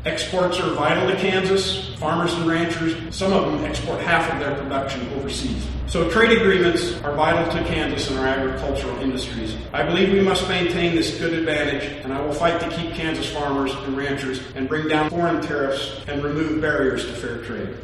A crowd of about 20 people gathered at the Union Pacific Depot in downtown Manhattan Tuesday